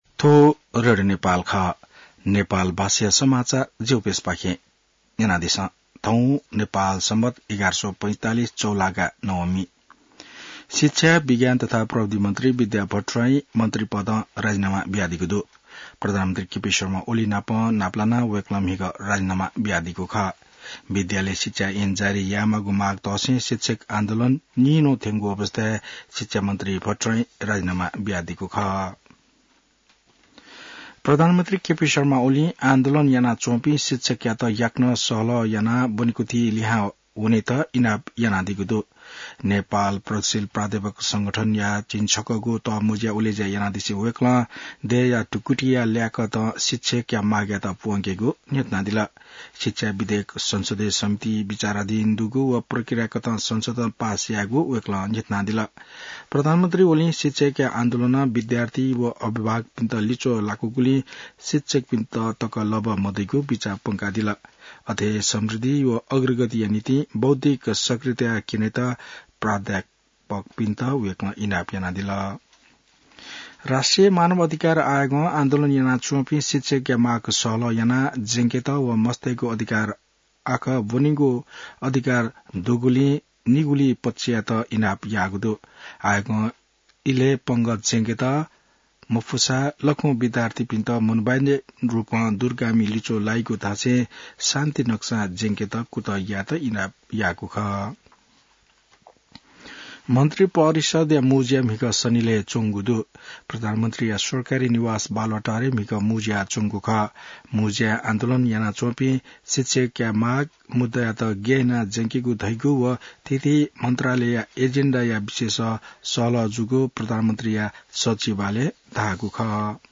नेपाल भाषामा समाचार : ९ वैशाख , २०८२